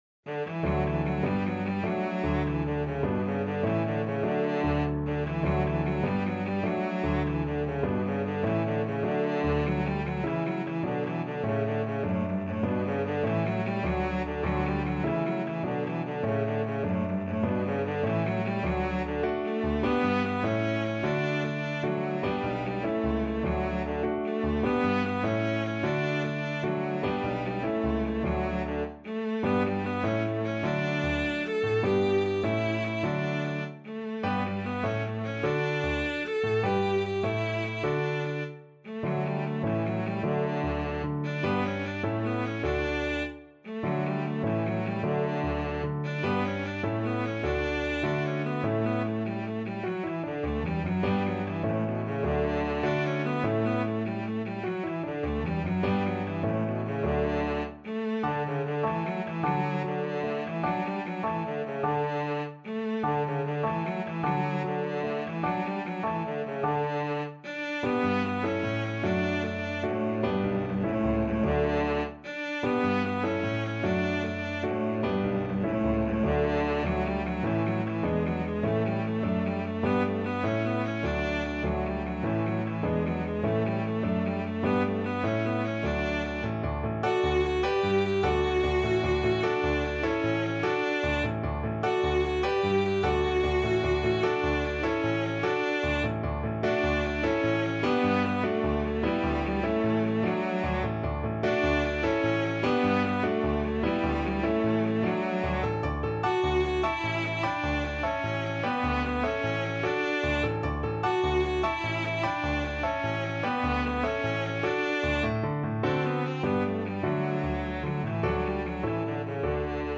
Cello
D major (Sounding Pitch) (View more D major Music for Cello )
6/8 (View more 6/8 Music)
Classical (View more Classical Cello Music)